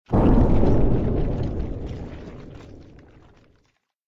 WallDecay3.ogg